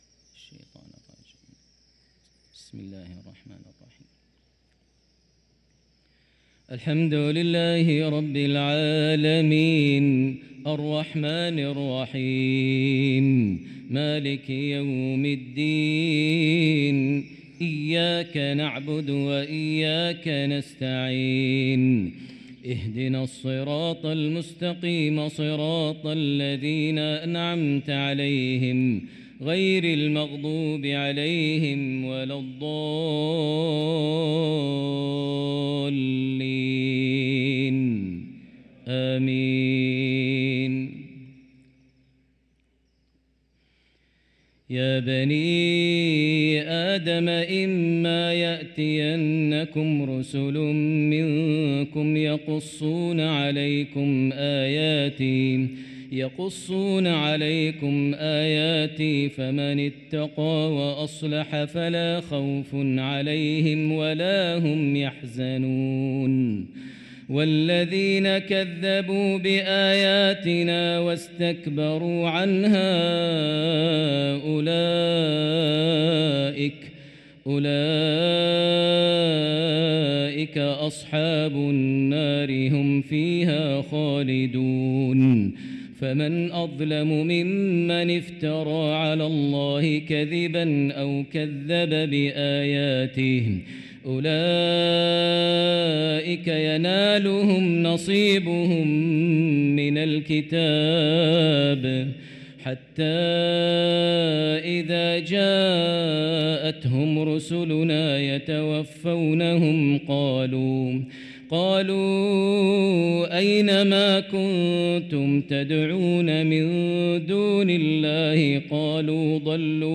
صلاة العشاء للقارئ ماهر المعيقلي 28 جمادي الأول 1445 هـ
تِلَاوَات الْحَرَمَيْن .